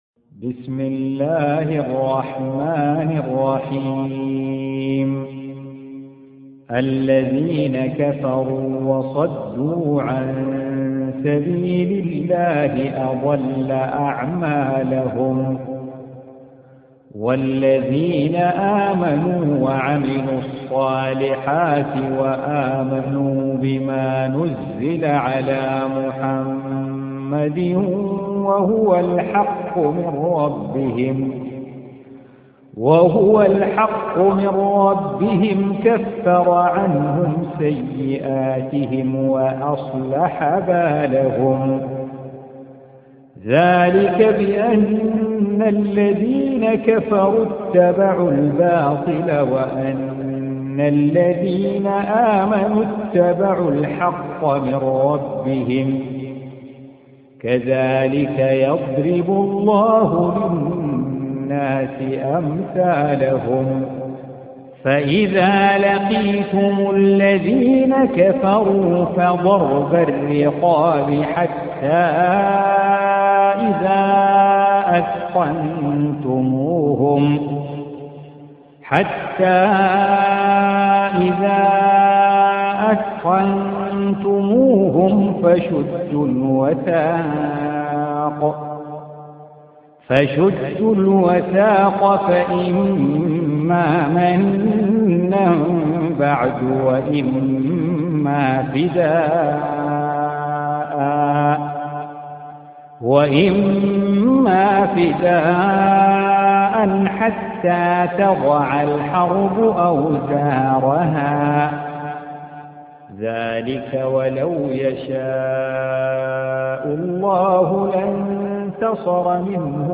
47. Surah Muhammad or Al-Qit�l سورة محمد Audio Quran Tarteel Recitation
حفص عن عاصم Hafs for Assem